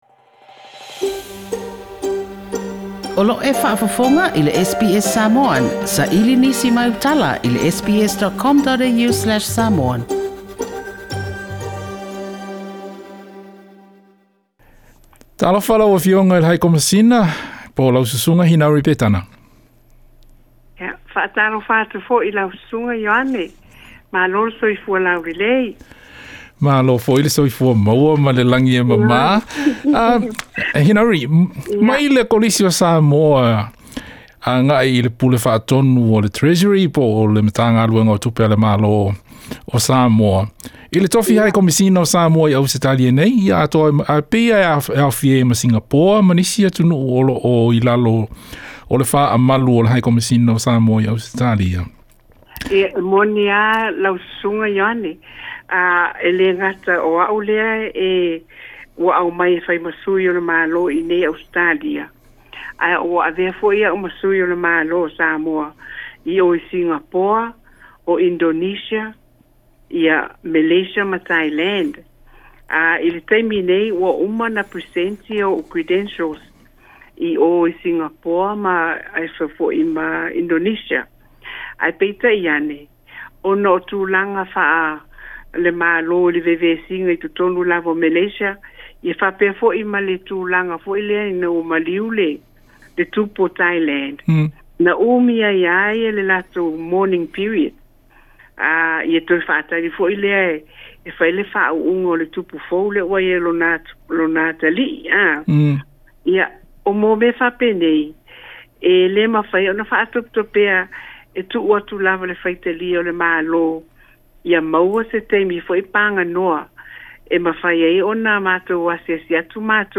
Se talanoaga ma le sui o Samoa i Ausetalia, le Afioga i le High Commisioner, Hinauri Petana.